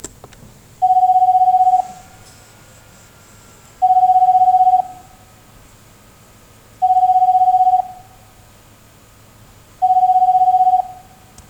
【NAKAYO（ナカヨ）ST101A 着信音サンプル】
■着信音　E